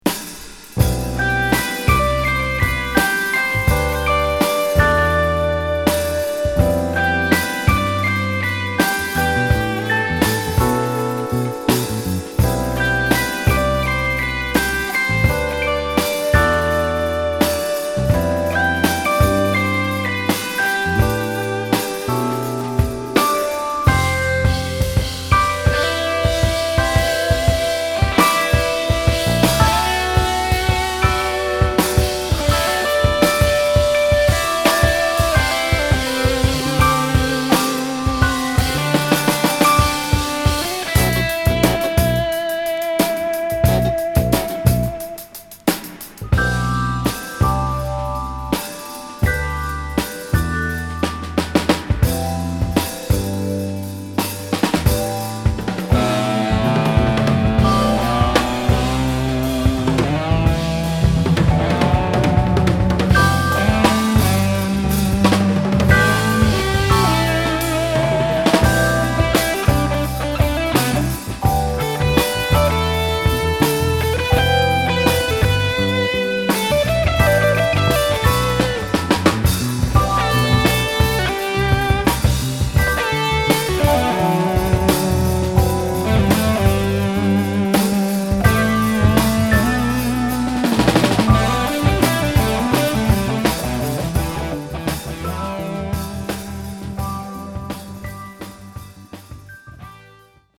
どの曲も流石の打ちっぷりです！